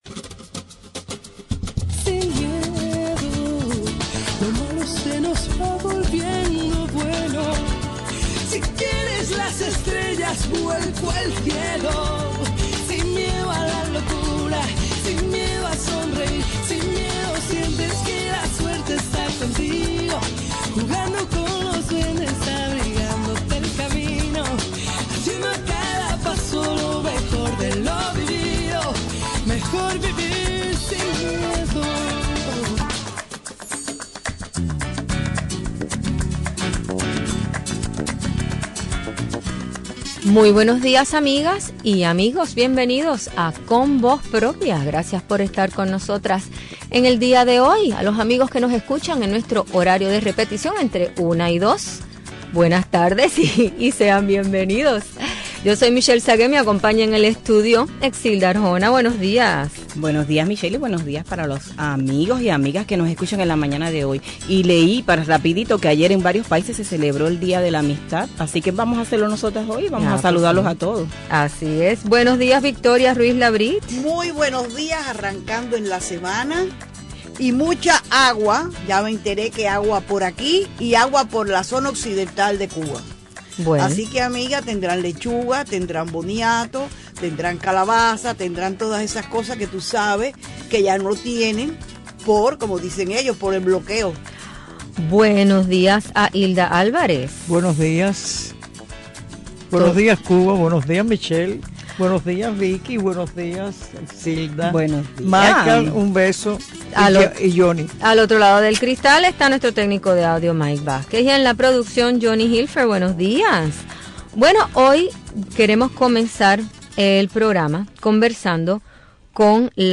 Hoy entrevistamos a la escritora